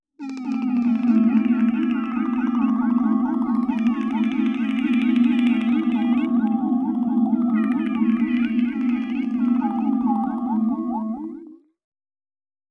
Sonidos: Especiales
Sonidos: Música